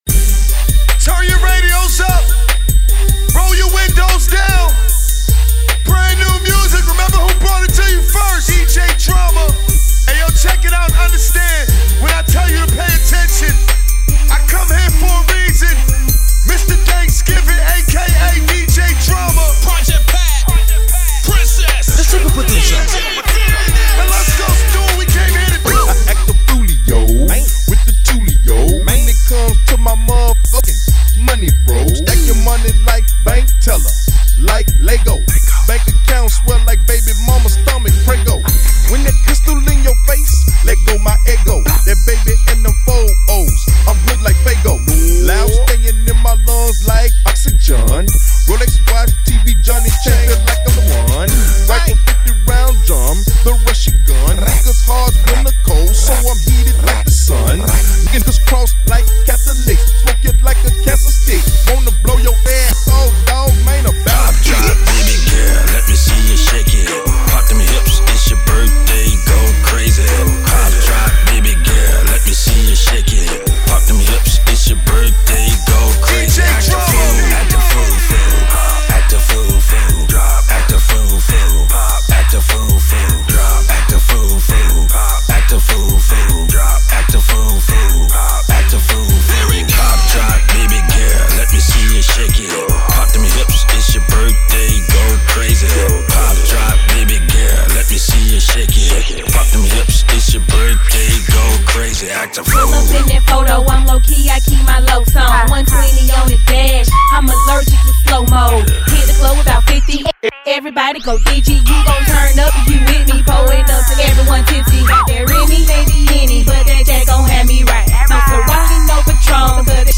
indie R&B/bedroom pop song